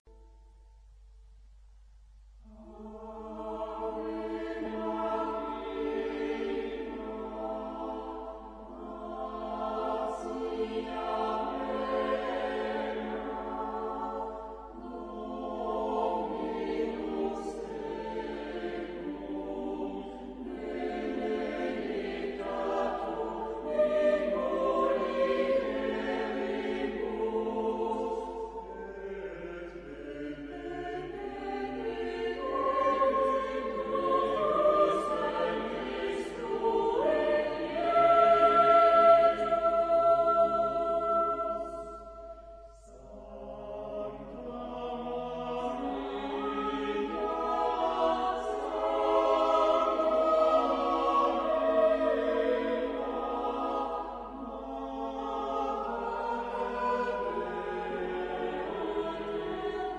Genre-Style-Form: Sacred ; Motet
Mood of the piece: meditative ; expressive
Type of Choir: SATB  (4 mixed voices )
Tonality: modal